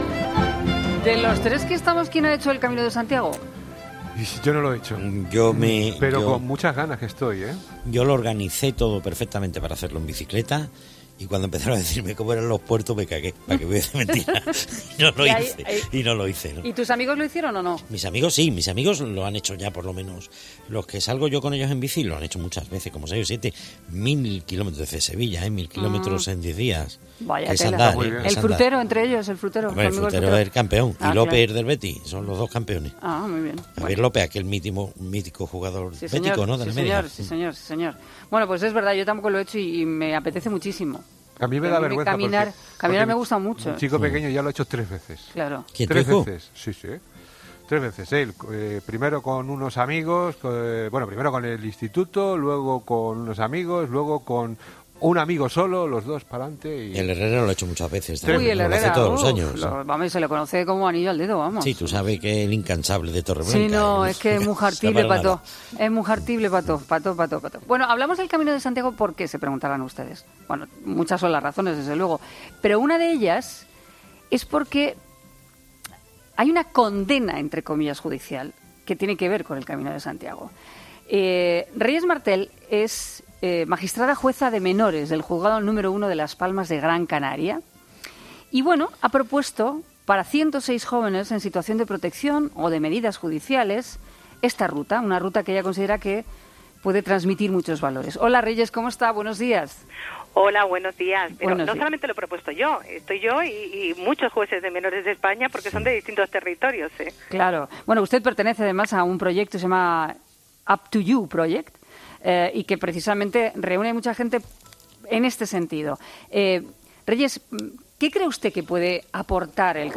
Reyes Martel, la magistrada que juzga a menores en Las Palmas de Gran Canaria, ha sido entrevistada este lunes en 'Herrera en Cope' a propósito de la medida de rehabilitación que ha propuesto para que los jóvenes infractores puedan enderezar sus vidas, y que consiste en hacer el Camino de Santiago.